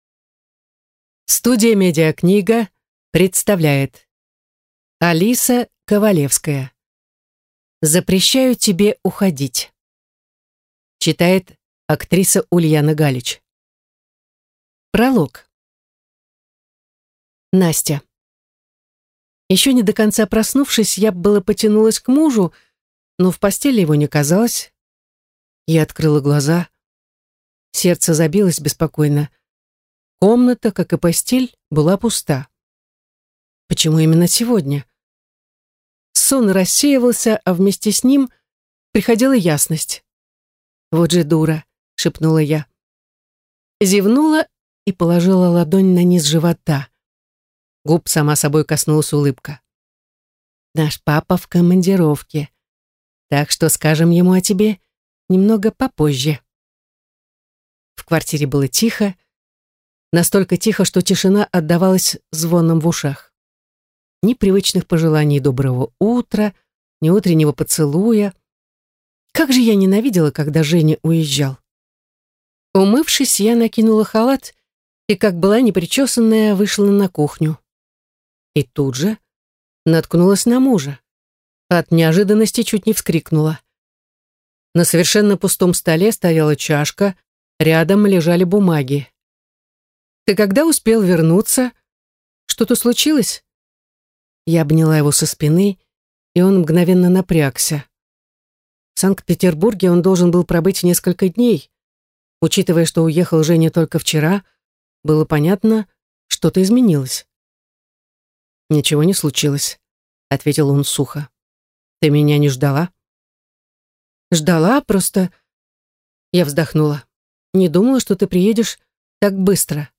Аудиокнига Запрещаю тебе уходить | Библиотека аудиокниг